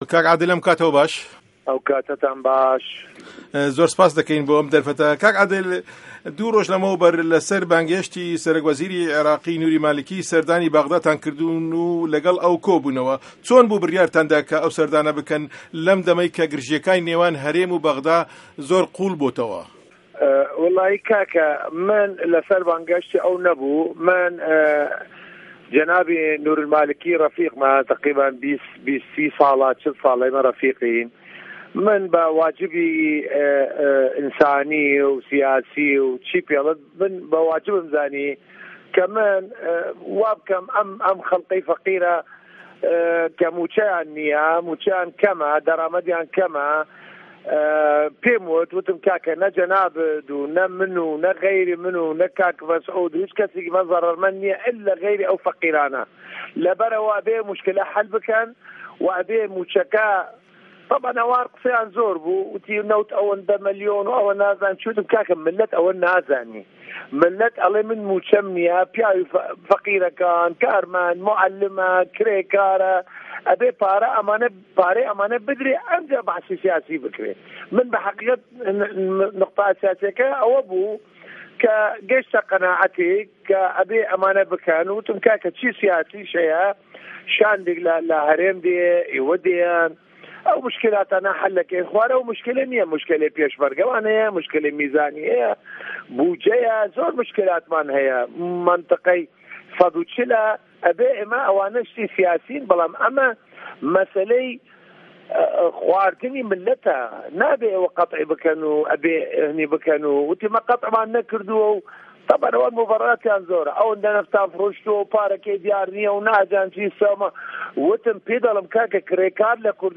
وتووێژی عادل موراد